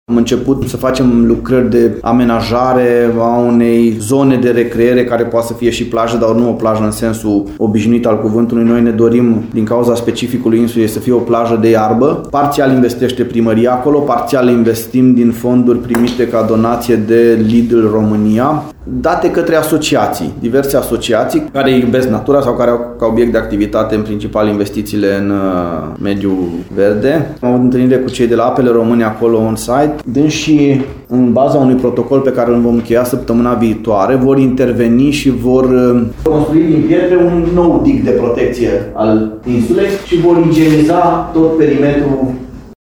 Pentru consolidarea malurilor, s-a cerut și intervenția celor de la Apele Române, spune primarul Claudiu Buciu.